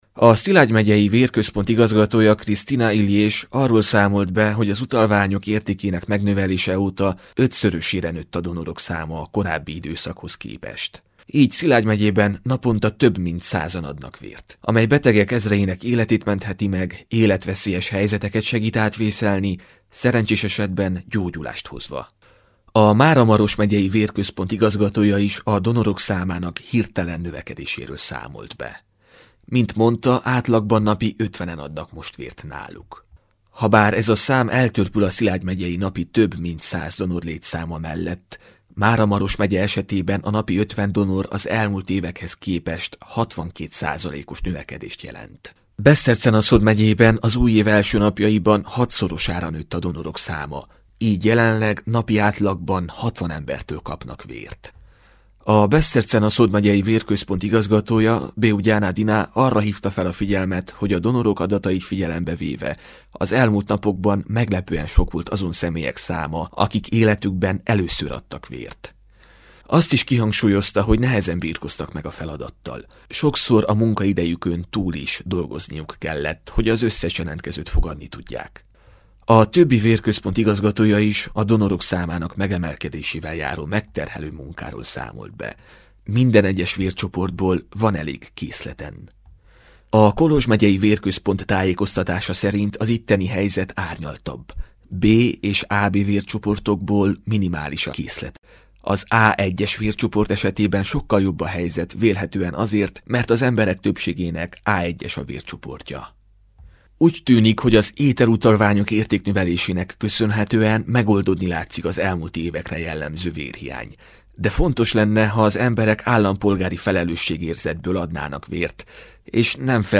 Felvettük a kapcsolatot több megyei vérközponttal – körkép adáskörzetünkből.
Riporter